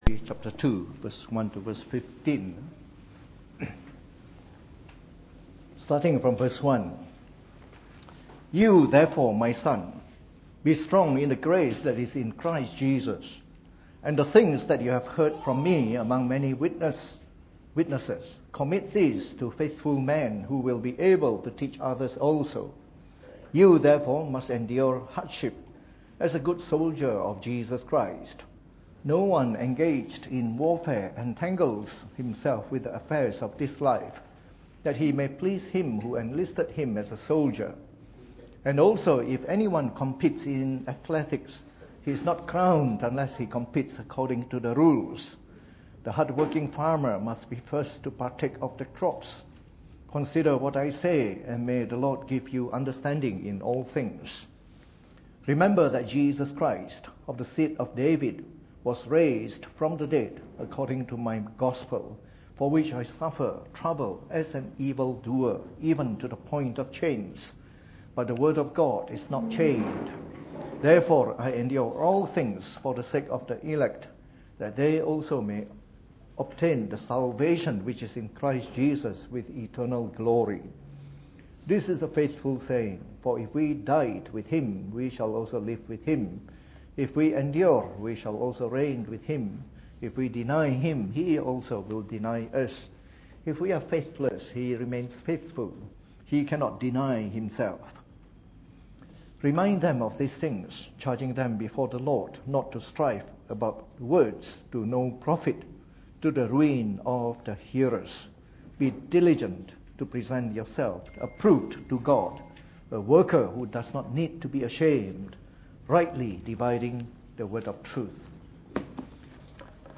Preached on the 22nd of November 2017 during the Bible Study.